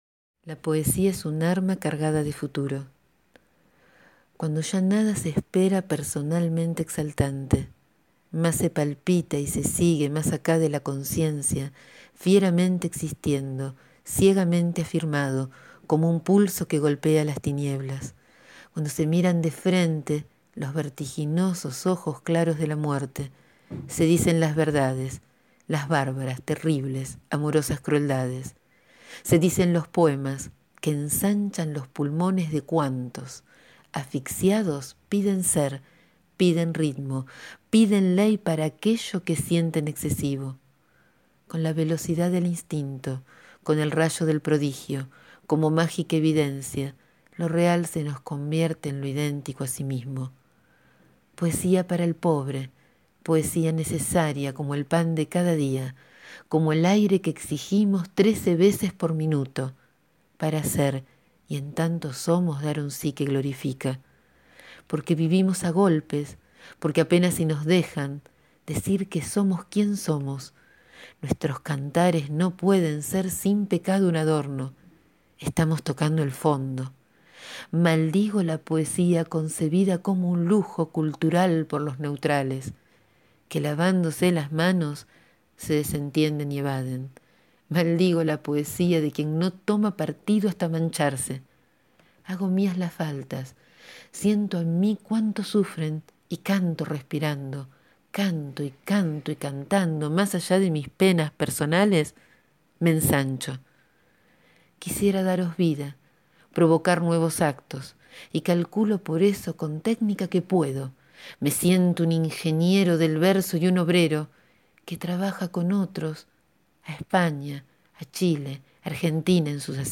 El poema es un grito al horizonte y en la tierra es un acto, un acontecimiento una revolución. Aquí lo dejo en mi voz!